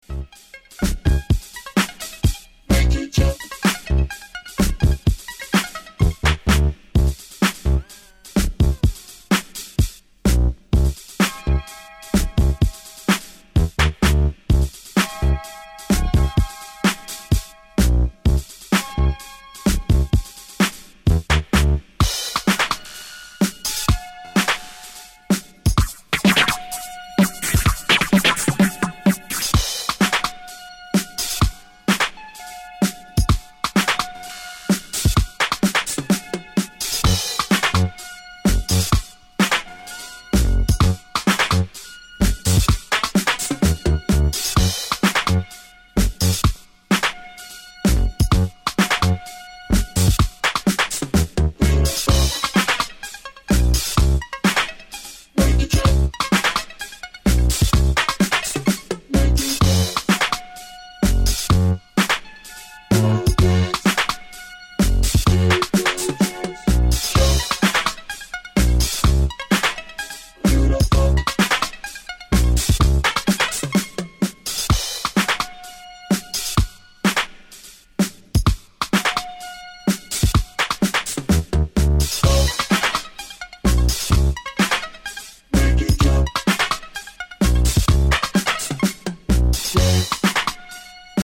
Classic electro repress.
Electro Detroit